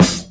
Snares
SGX_SNR3.wav